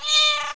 cat6.wav